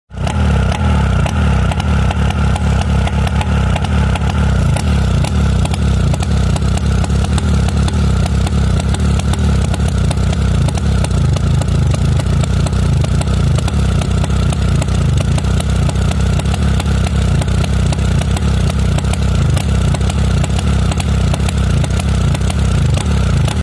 Звуки мотоциклов
Звук выхлопа мотоцикла Ява: особенности и характер